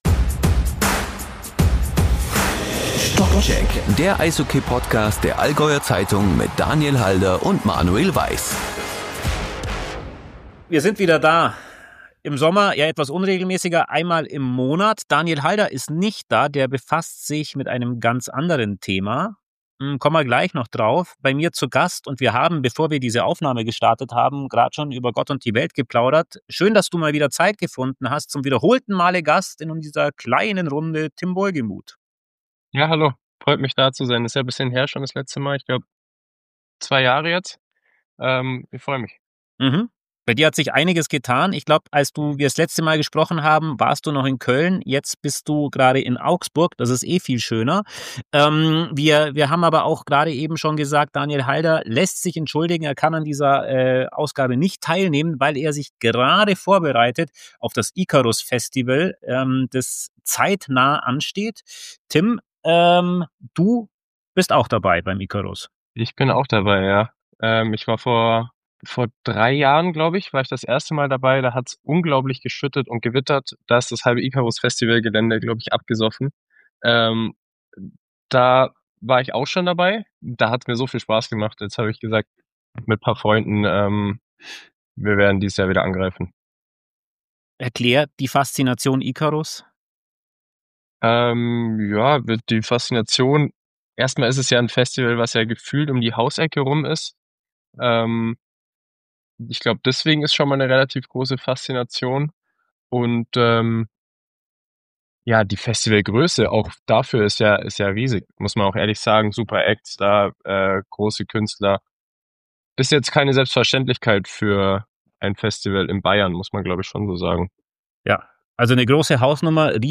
Auch aufs Fraueneishockey werfen wir regelmäßig einen Blick und sprechen in jeder Folge mit einem Experten aus der Eishockeyszene: In Stockcheck kommen Spieler, Trainer, Fans und Insider zu Wort.